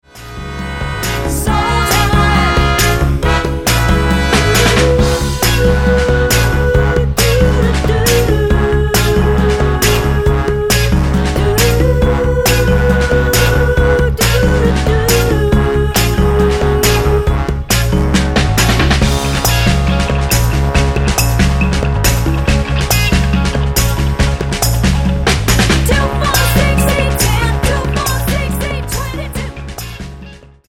Tonart:Db mit Chor
Die besten Playbacks Instrumentals und Karaoke Versionen .